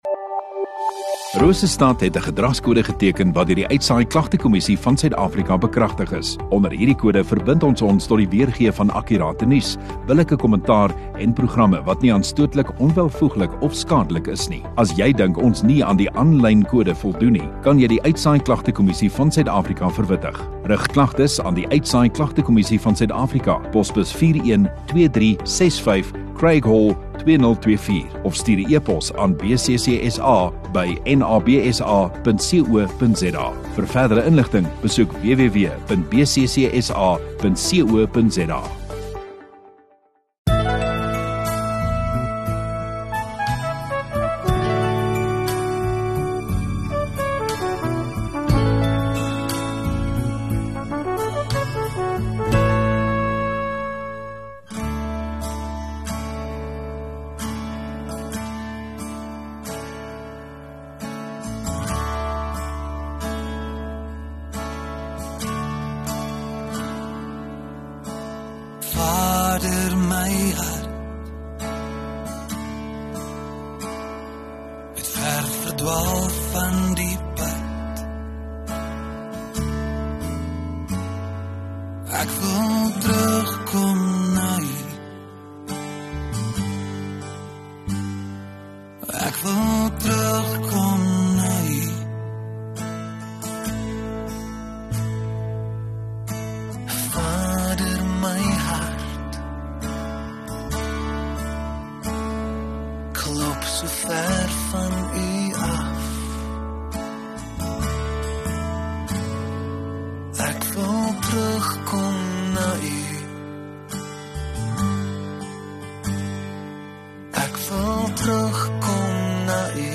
24 Aug Saterdag Oggenddiens